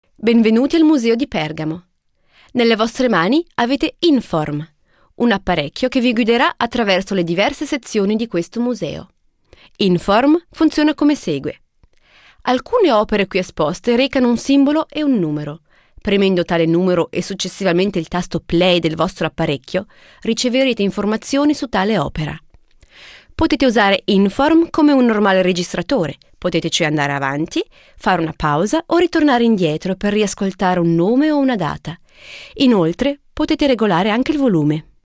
Schauspielerin und Sprecherin, Muttersprache Italienisch, Deutsch mit F od. I Akzent, E und F mit I Akzent, breites Spektrum an Stimmlagen , Gesang.
Sprechprobe: Industrie (Muttersprache):
Native speaker (italian) and actress.Can speak german, french and english with french or italian accent.